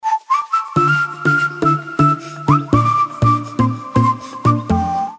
короткие
озорные
Позитивный свист на уведомления